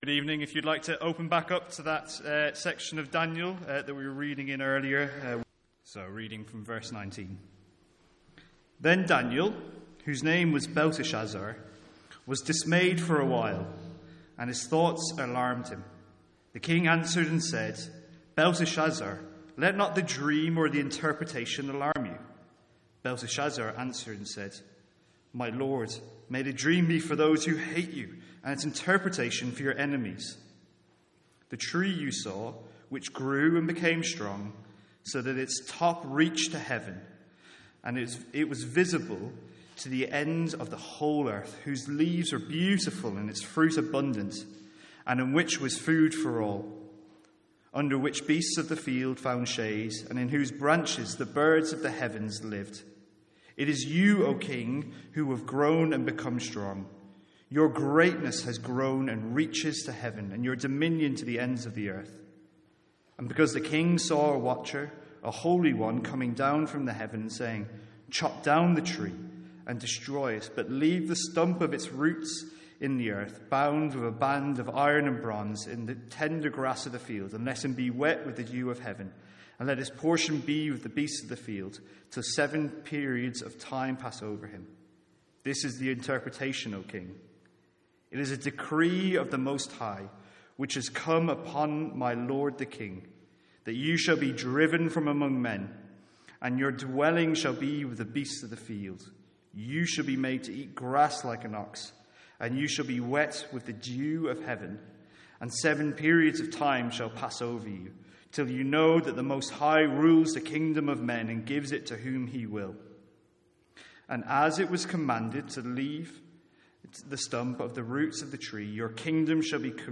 Sermons | St Andrews Free Church
From our evening series in Daniel.